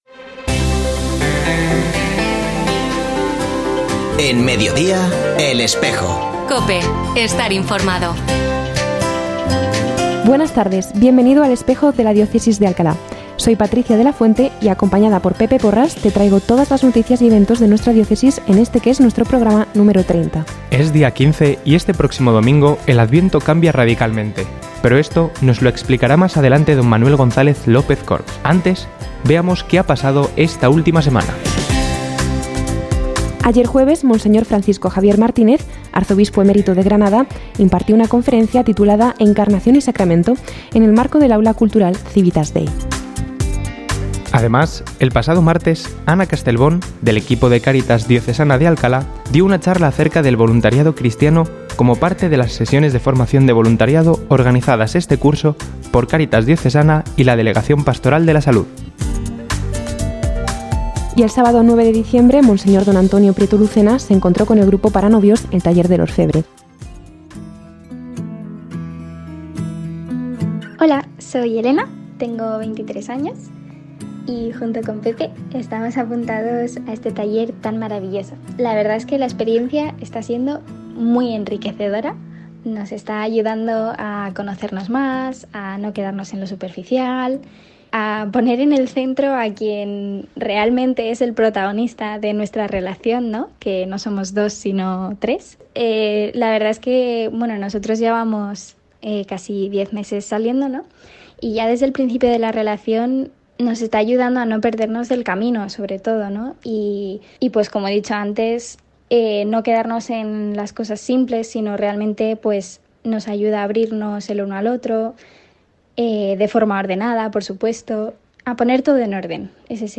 Ofrecemos el audio del programa de El Espejo de la Diócesis de Alcalá emitido hoy, 15 de diciembre de 2023, en radio COPE. Este espacio de información religiosa de nuestra diócesis puede escucharse en la frecuencia 92.0 FM, todos los viernes de 13.33 a 14 horas.